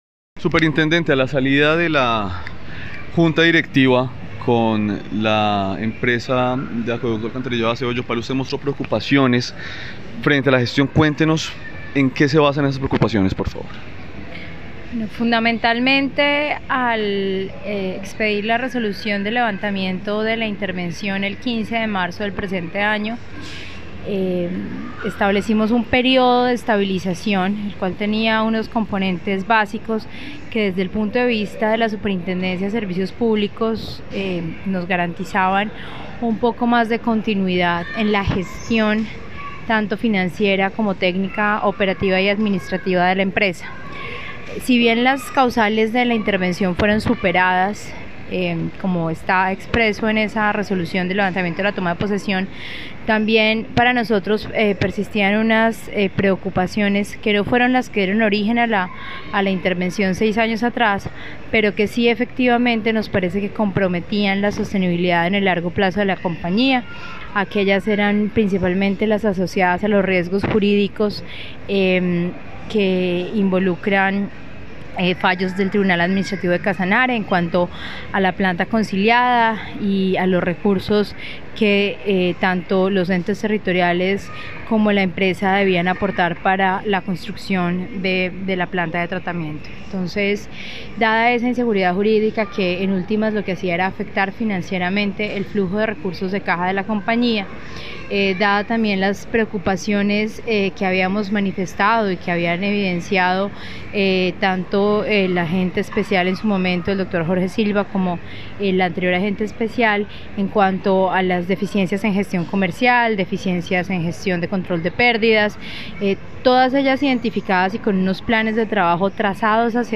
Declaraciones Superintendente Natasha Avendaño García